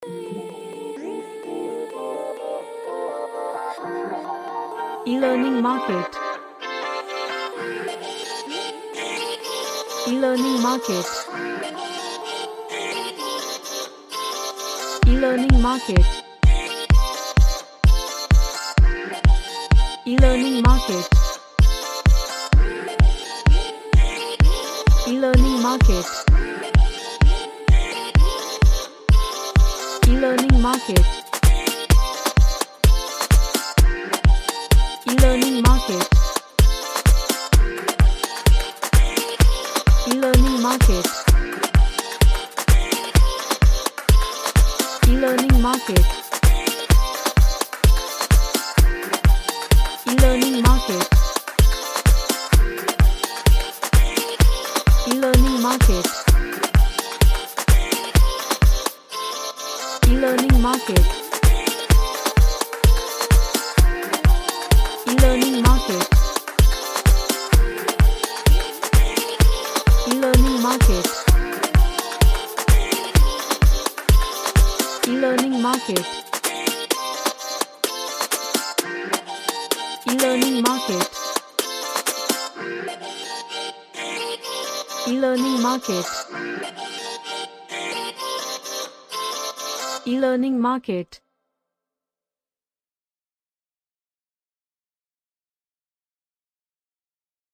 A moombah track featuring vocal chops